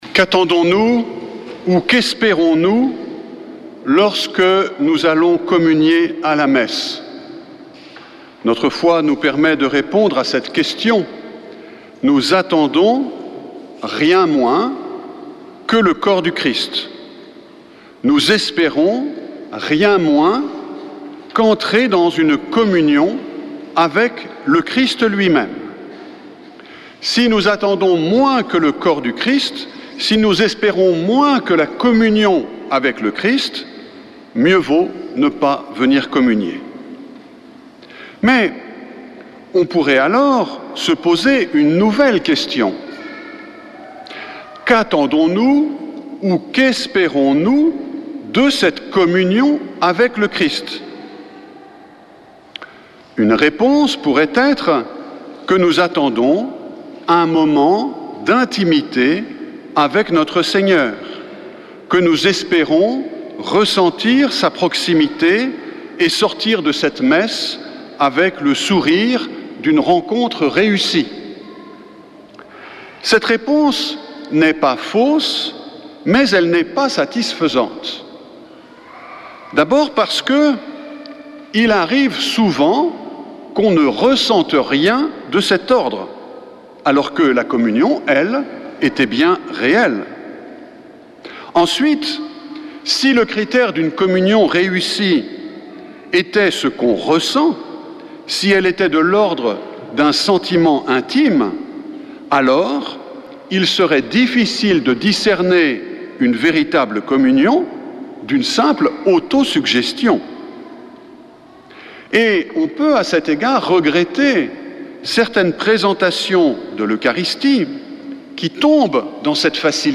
Messe depuis le couvent des Dominicains de Toulouse
Homélie du 18 août